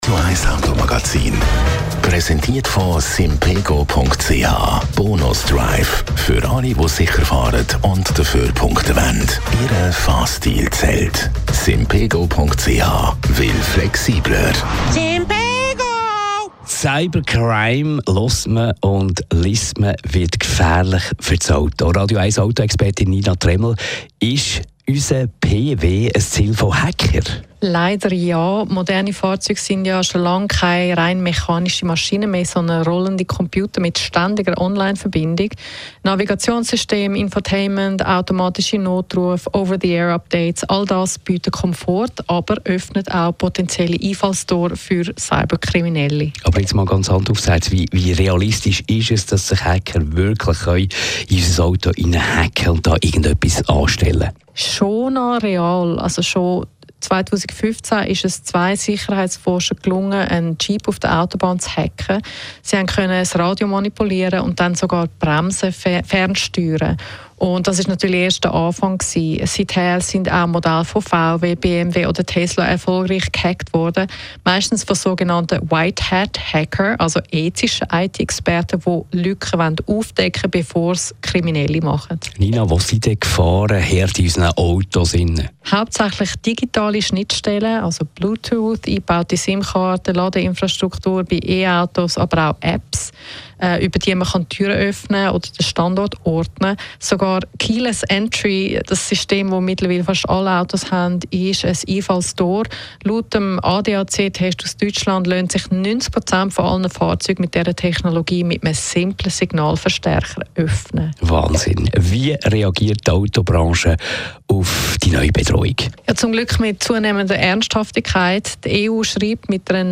Autoexpertin